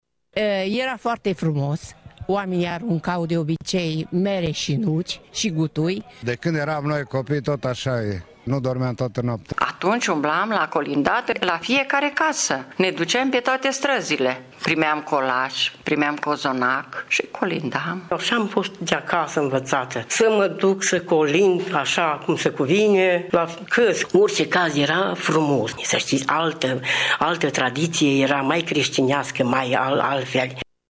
Tîrgumureşenii îşi aduc aminte cu plăcere de vremurile în care aceste tradiţii erau păstrate cu sfinţenie, şi spun că încearcă şi acum să ducă mai departe obiceiurile.